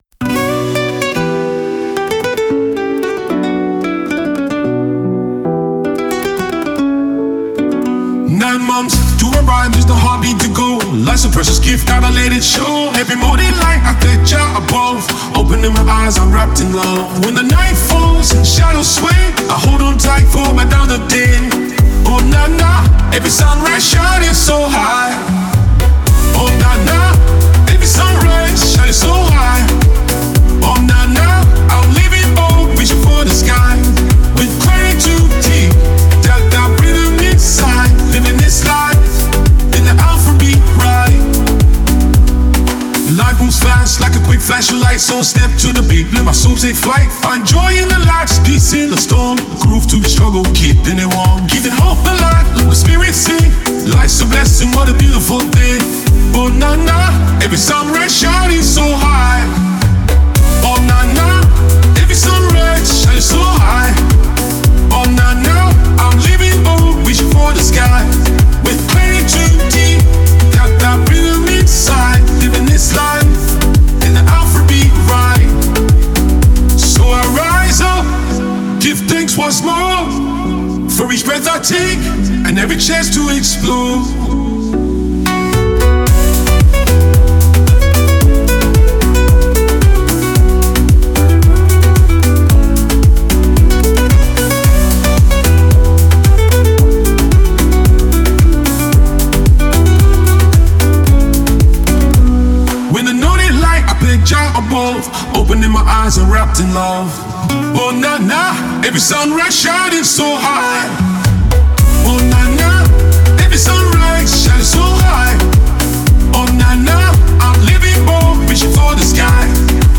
Nigeria Music
With a melodious hook and genuine words
smooth silky voice glides over the infectious beat